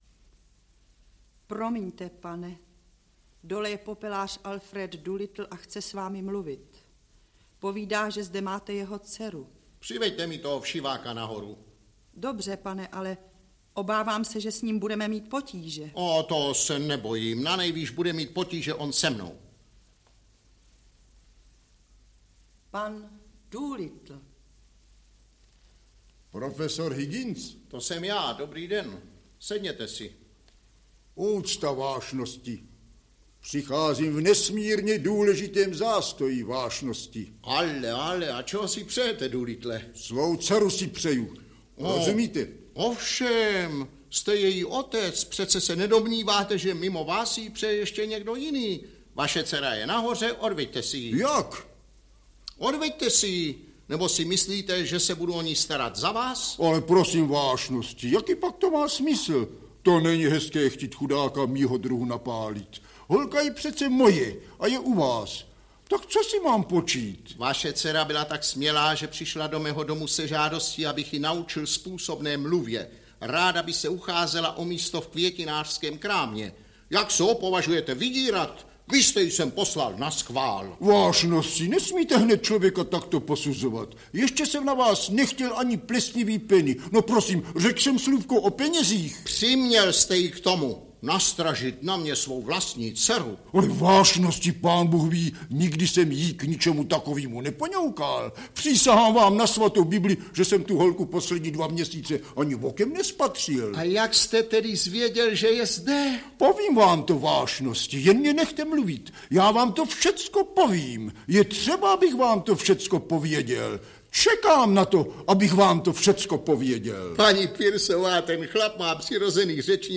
Svatá Jana - ukázky z her - George Bernard Shaw - Audiokniha
• Čte: Jiří Steimar, Jaroslav Vojta, Jiřina…